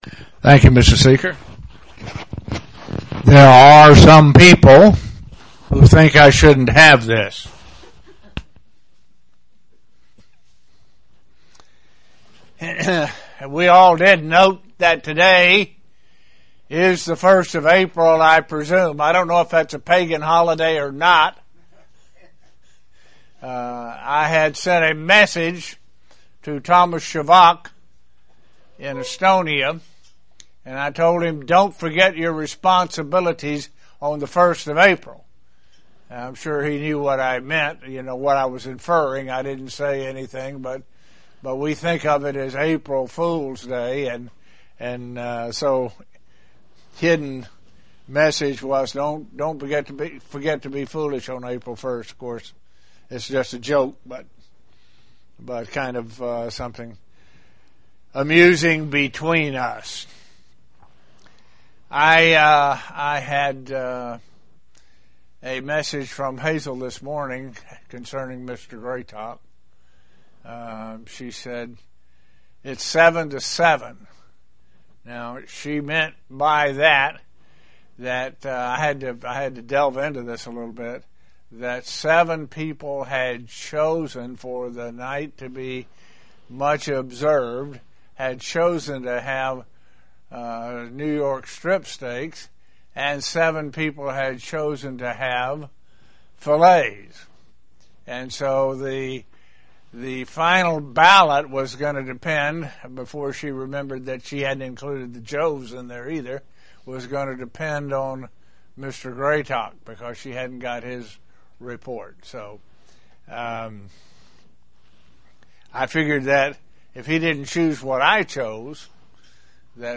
Sermons
Given in Elmira, NY